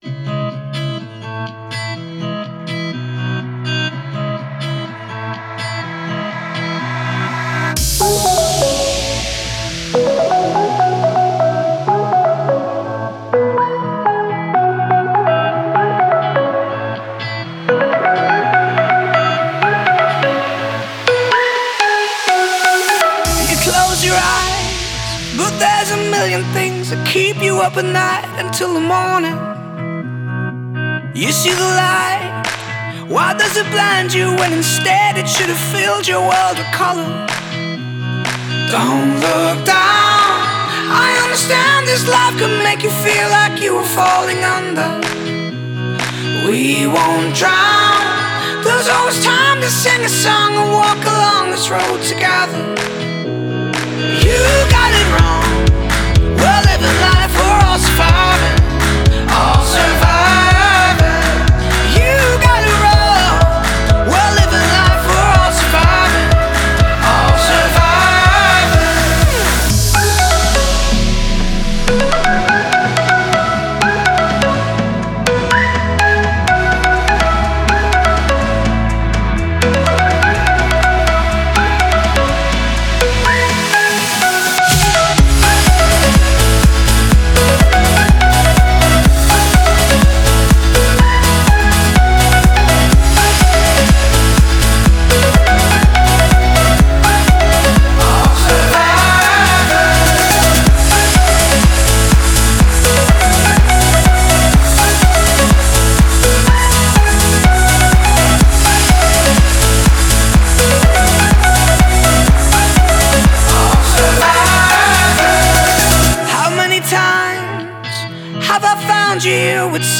Главная » Файлы » Клубная Музыка Категория